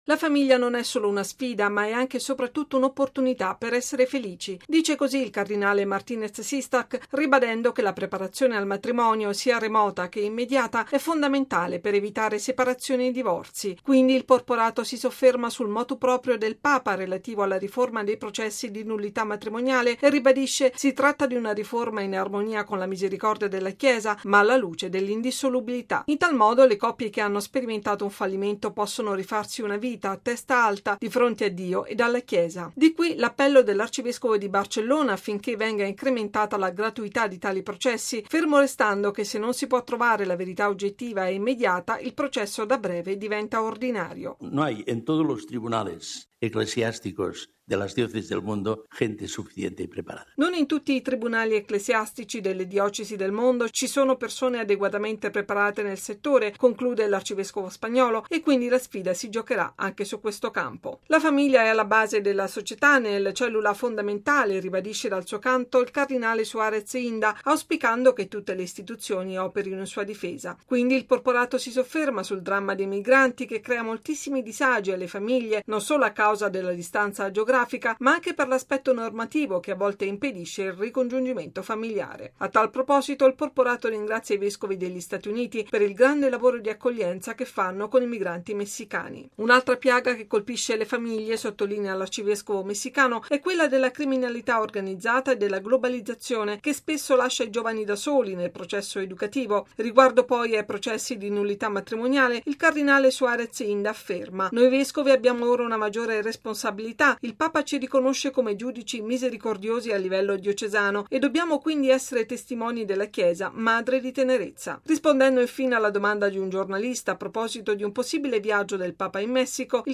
Intanto, stamani il consueto briefing in Sala Stampa vaticana. Sono intervenuti tre cardinali: lo spagnolo Lluis Martinez Sistach, arcivescovo di Barcellona, il sudafricano Wilfrid Fox Napier, arcivescovo di Durban, e il messicano Alberto Suárez Inda, arcivescovo di Morelia, in Messico.